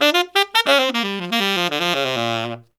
Index of /90_sSampleCDs/Zero-G - Phantom Horns/SAX SOLO 2